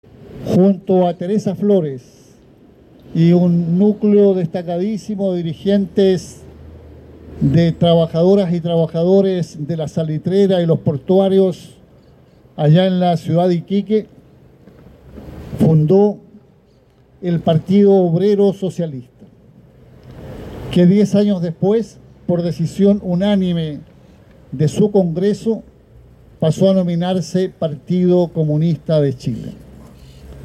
Junto a miembro de la Comisión Política del Partido Comunista y acompañado de una delegación de dirigencias comunales, regionales y militantes, el presidente de la colectividad, Lautaro Carmona, dio cuenta del aporte histórico y político de Luis Emilio Recabarren, fundador del partido de izquierda, a cien años de su fallecimiento.